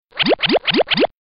Звуки мультяшных шагов
Бульк бульк